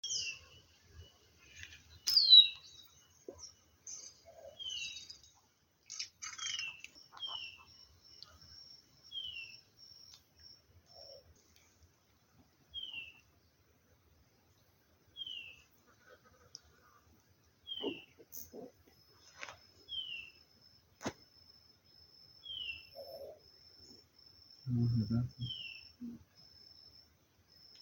Tangará Bonito (Chlorophonia cyanea)
Nombre en inglés: Blue-naped Chlorophonia
Localidad o área protegida: Reserva Privada y Ecolodge Surucuá
Condición: Silvestre
Certeza: Vocalización Grabada